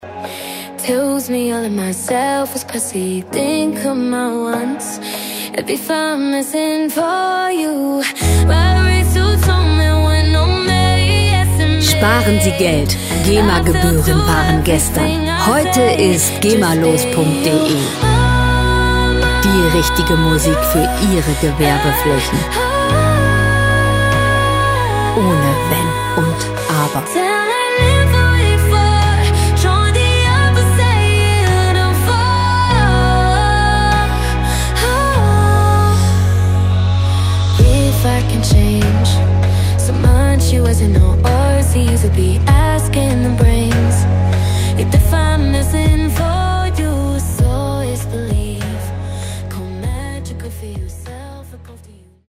Lounge Musik - Romanzen
Musikstil: Pop Ballad
Tempo: 73 bpm
Tonart: H-Dur
Charakter: gefühlsbetont, weich
Instrumentierung: Popsängerin, Synthesizer